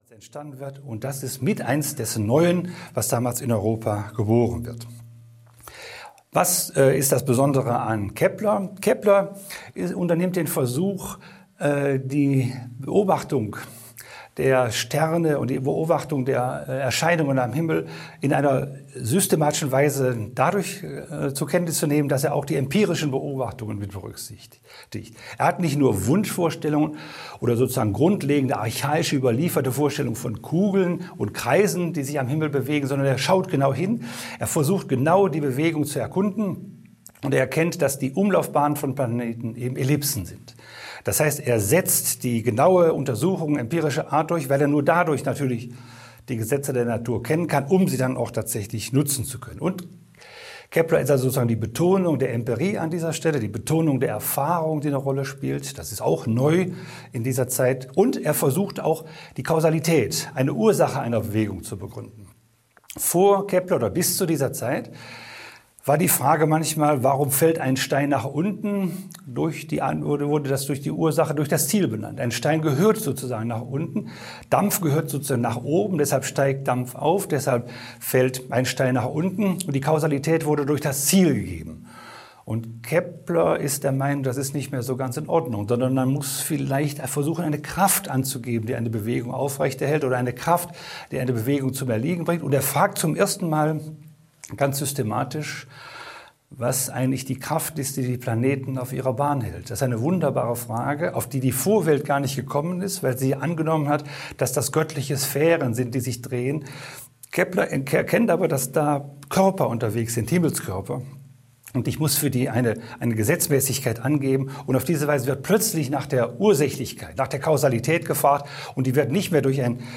Als das Neue noch neu war - Ernst Peter Fischer - Hörbuch